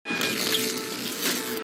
Download Tripod heat ray sound button